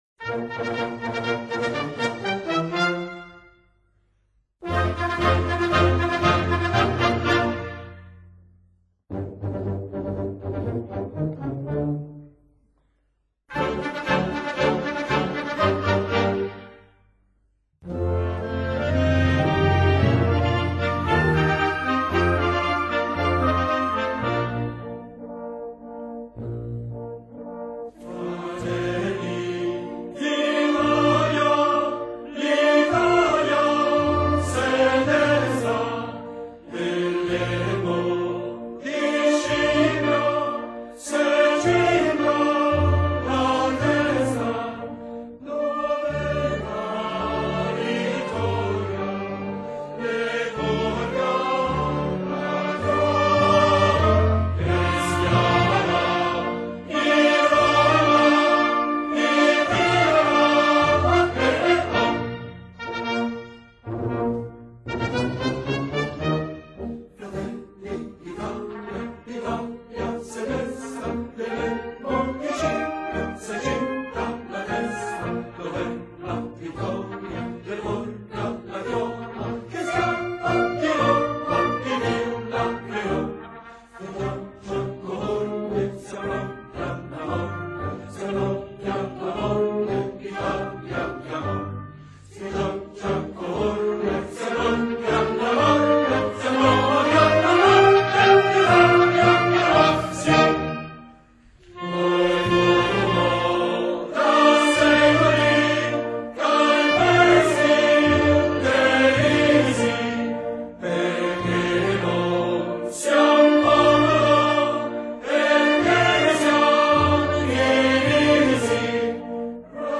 |thumb|전체 노래 버전
미켈레 노바로가 작곡한 "일 칸토 델리 이탈리아니"(이탈리아인의 노래)는 전형적인 행진곡풍의 4/4 박자 곡으로, 내림 나장조이다.[4] 기억하기 쉬운 선율리듬을 가지고 있어 대중에게 쉽게 전파되었다.[4] 화성과 리듬은 더 복잡한 구성을 보인다.